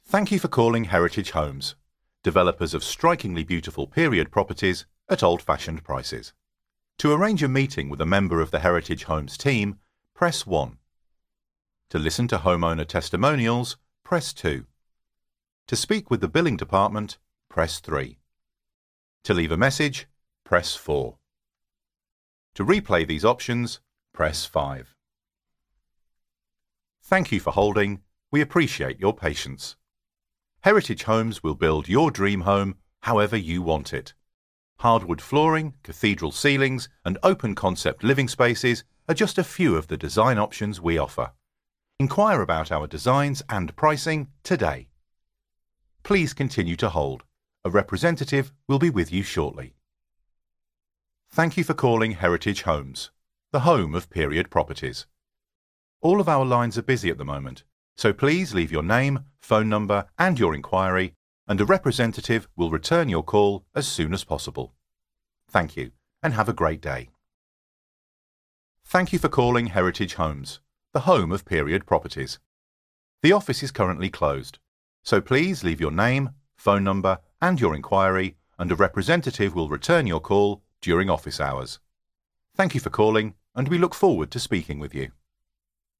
Inglês (britânico)
Microfone: SHURE SM7B